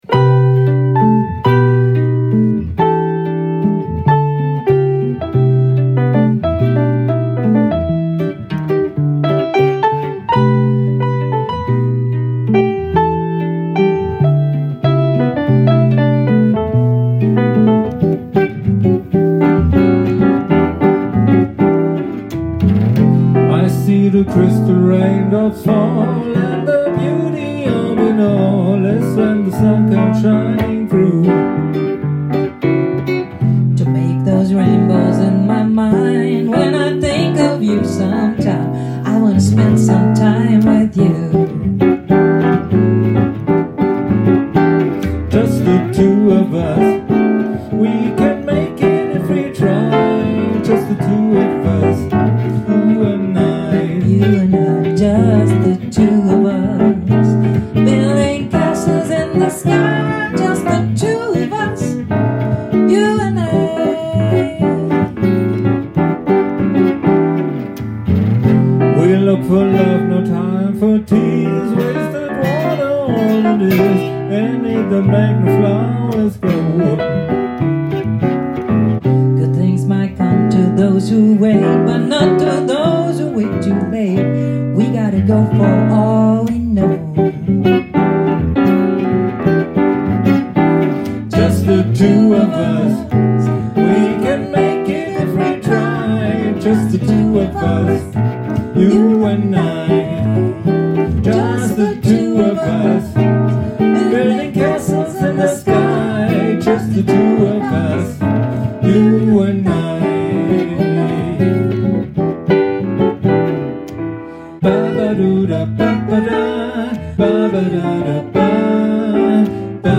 Swing, Soul-Jazz, Funk und Bossanova und Pop